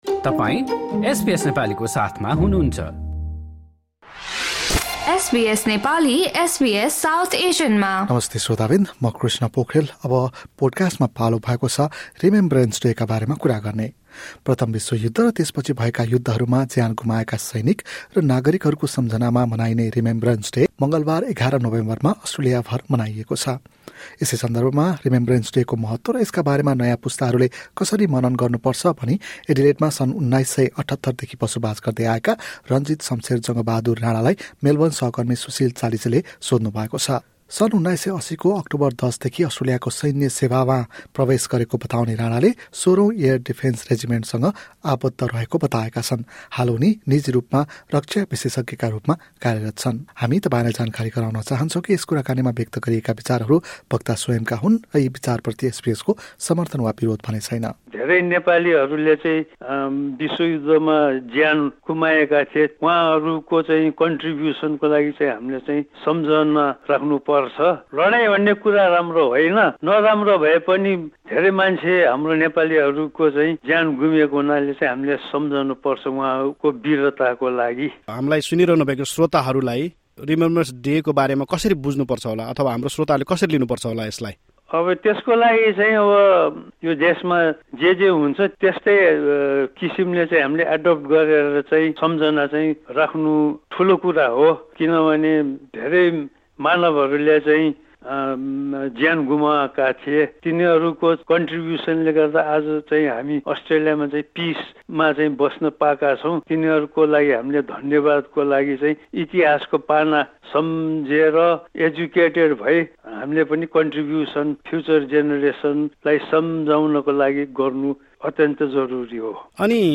SBS Nepali spoke with veterans about the significance of this day and how younger generations reflect on it.